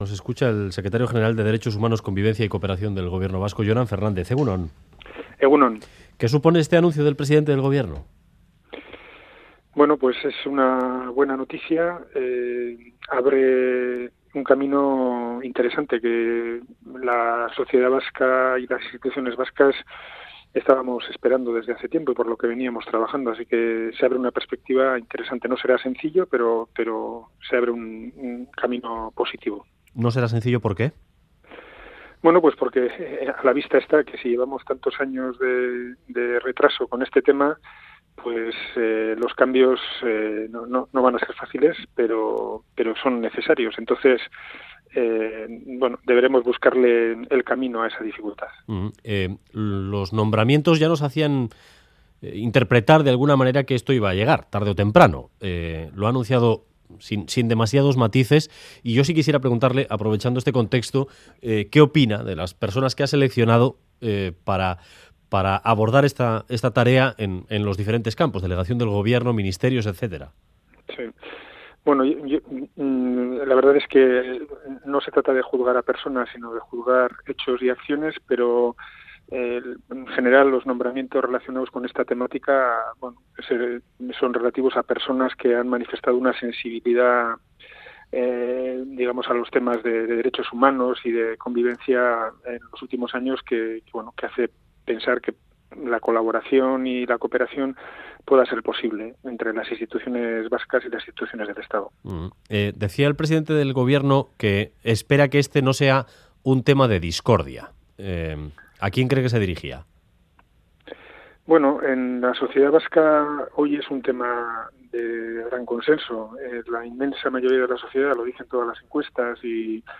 Audio: Jonan Fernández valora en Radio Euskadi el anuncio de Pedro Sánchez sobre el cambio de la política penitenciaria.